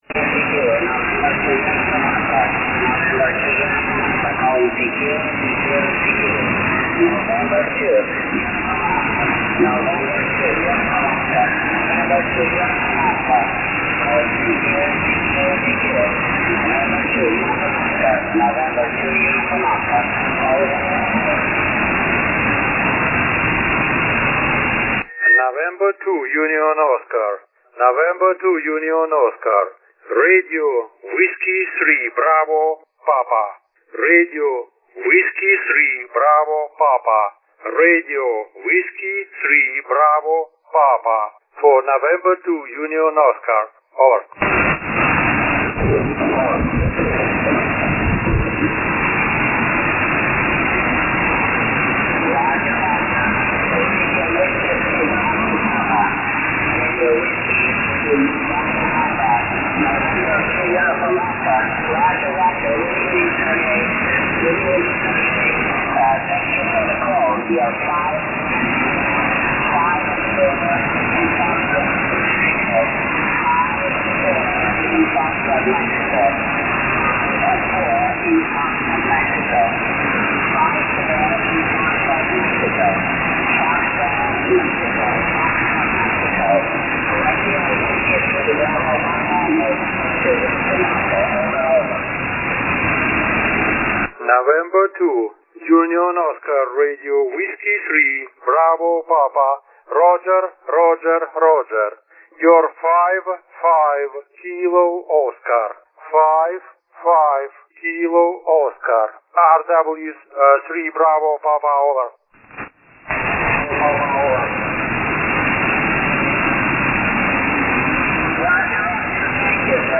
LNA на MGF4919G, NF=0.13dB. Шум Солнца перед контестом 18dB.
Ниже приведены фрагменты записи моих связей в формате mp3. Длительность своей передачи урезал с помощью редактора.